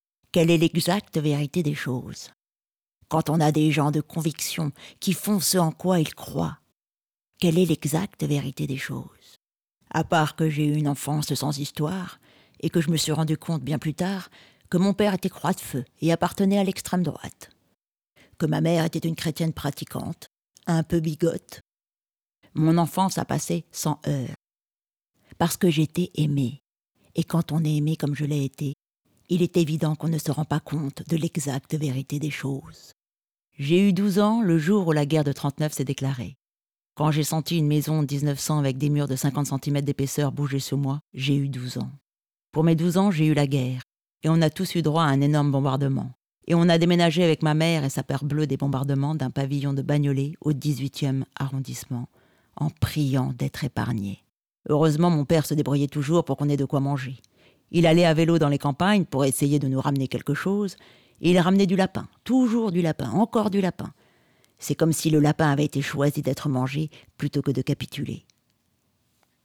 Voix de vieille dame - Les Antigones, Laurent Leclerc
VOIX-VIEILLE-DAME.wav